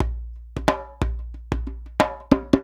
90 JEMBE7.wav